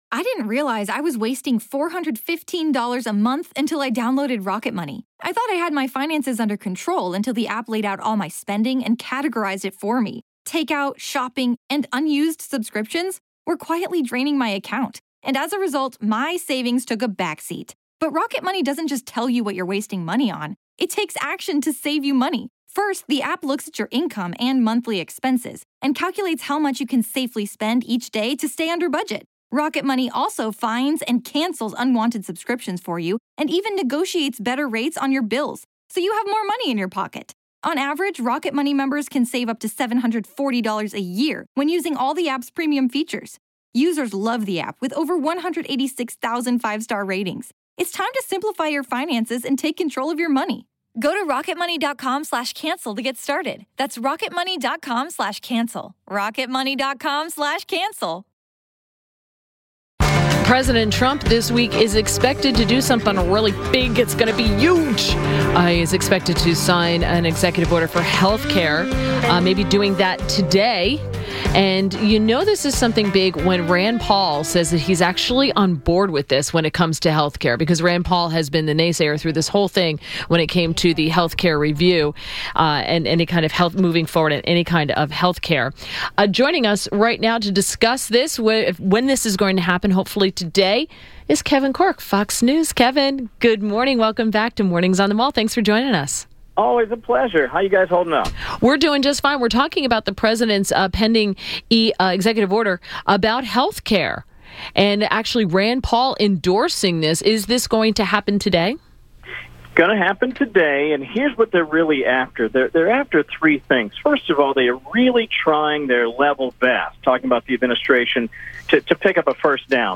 WMAL Interview - KEVIN CORKE - 10.12.17
INTERVIEW -- KEVIN CORKE - Fox News White House Correspondent – discussed what's on President Trump's agenda today.